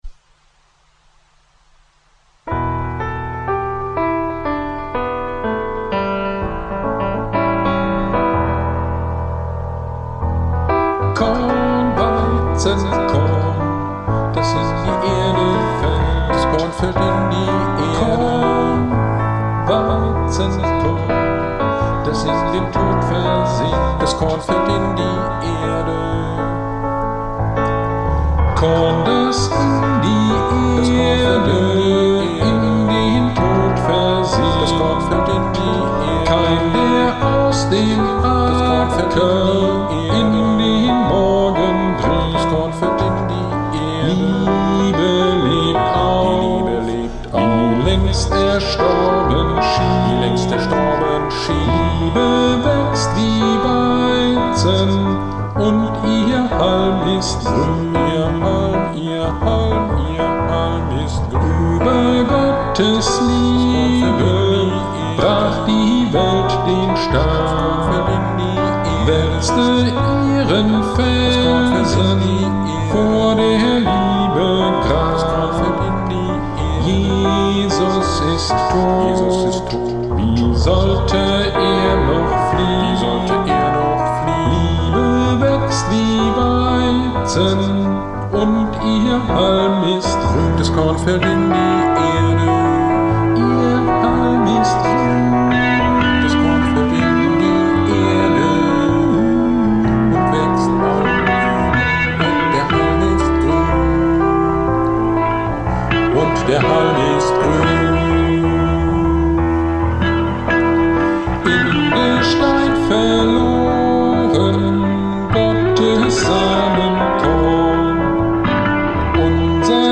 Predigt am 14.03.21 zu Johannes 12,20-24 - Kirchgemeinde Pölzig